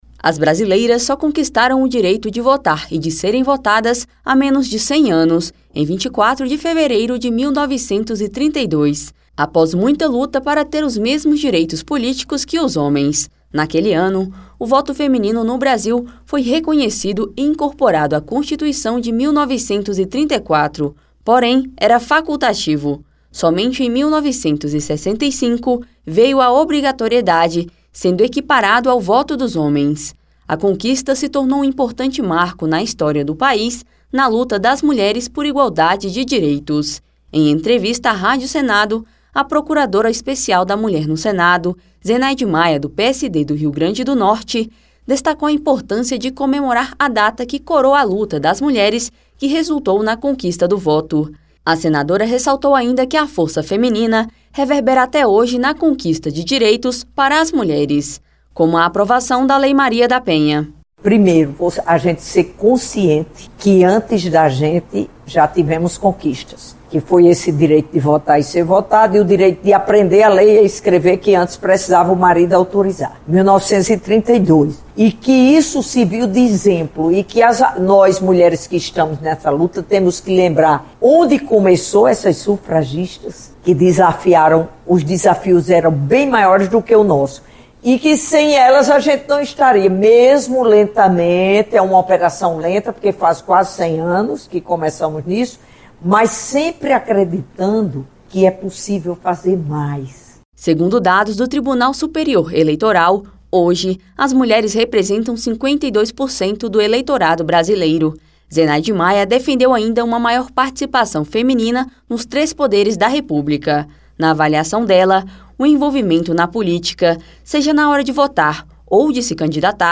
As brasileiras só conquistaram o direito de votar e de serem votadas há menos de 100 anos, em 24 de fevereiro de 1932. Em entrevista à Rádio Senado, a procuradora Especial da Mulher no Senado, Zenaide Maia (PSD-RN), destacou a importância de comemorar a data e defendeu maior participação feminina nos três poderes da República.